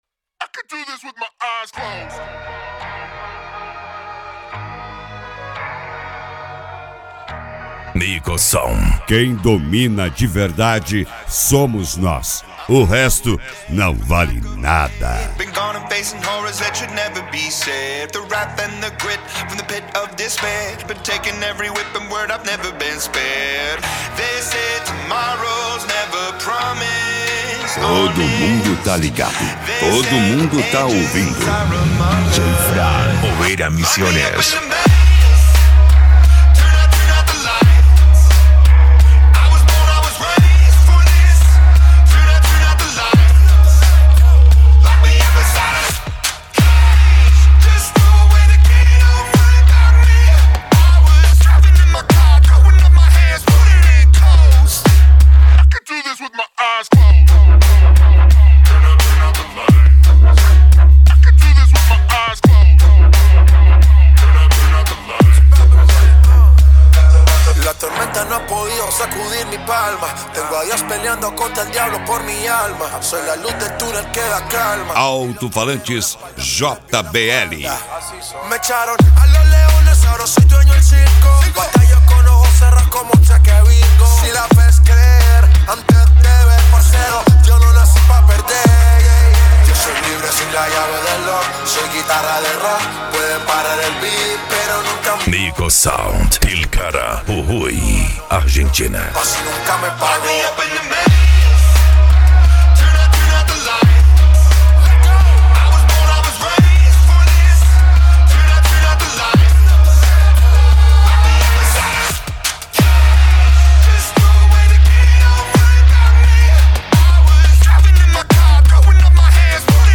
Bass
Electro House
Eletronica
Psy Trance
Remix